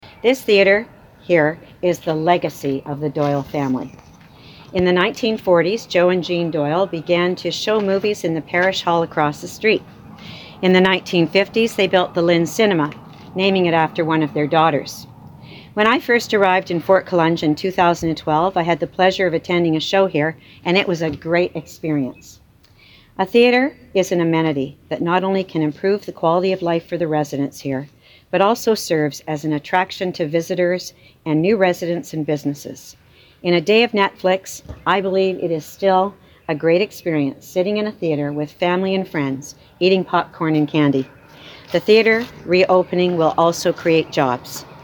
Pontiac Warden Jane Toller held a small press conference on Monday morning (April 4) to announce that she has purchased the Cinéma Lyn, located in downtown Fort-Coulonge at 526 rue Baume.
Clip-Jane-Toller-theatre.mp3